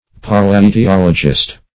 Search Result for " palaetiologist" : The Collaborative International Dictionary of English v.0.48: Palaetiologist \Pa*l[ae]`ti*ol"o*gist\, n. One versed in pal[ae]tiology.
palaetiologist.mp3